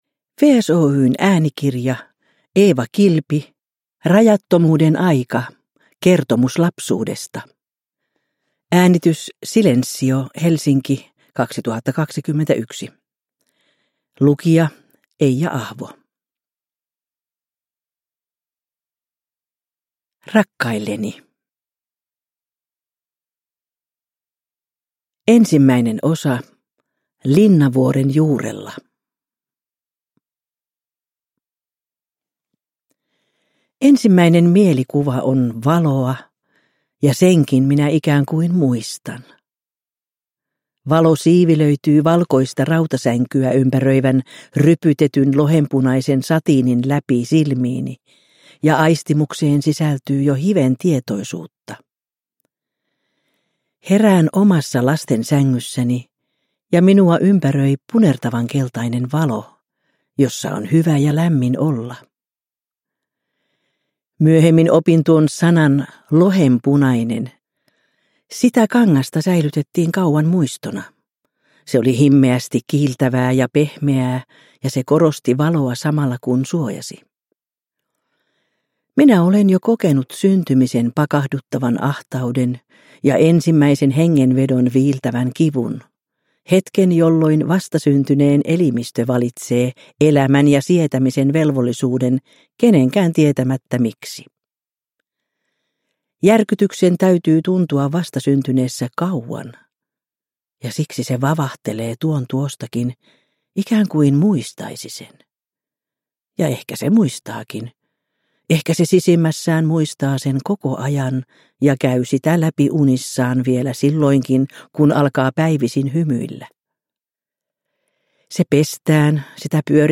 Rajattomuuden aika – Ljudbok – Laddas ner